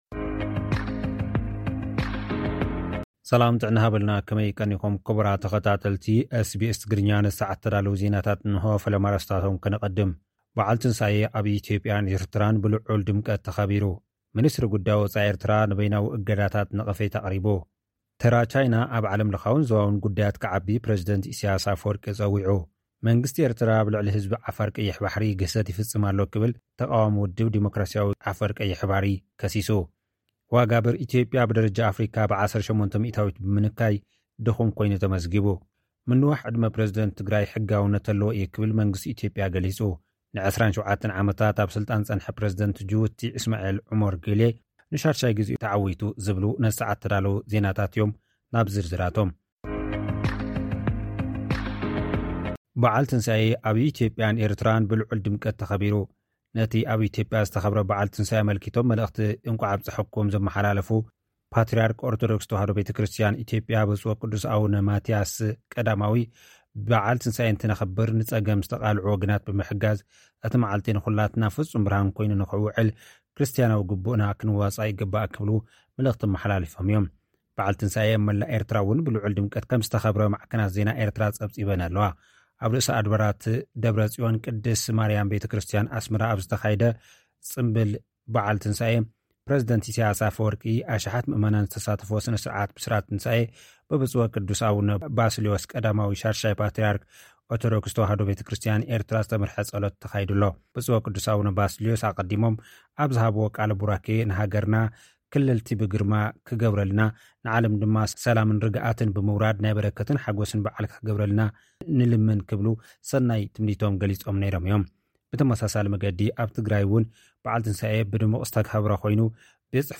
በዓል ትንሳኤ፡ ኢትዮጵያን ኤርትራን ብልዑል ድምቀት ተኸቢሩ። (ጸብጻብ)